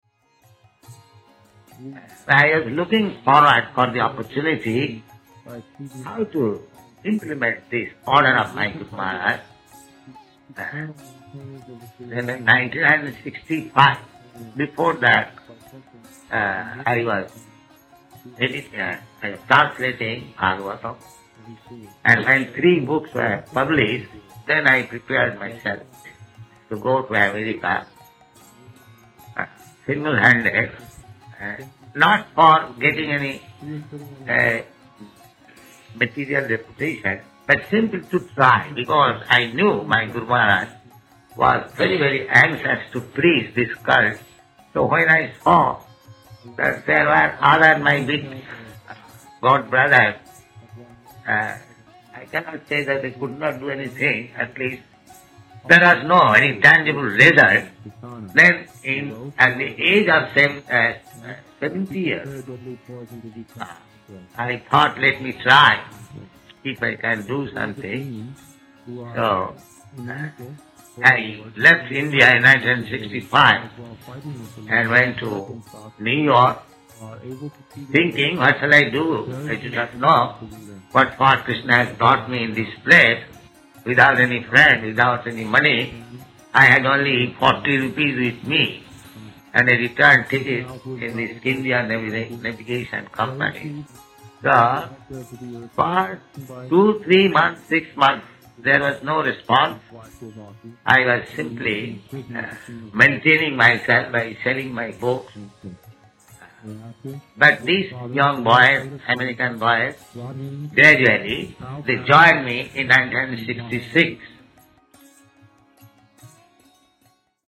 (740211 - Lecture Festival Appearance Day, Bhaktisiddhanta Sarasvati - Vrndavana)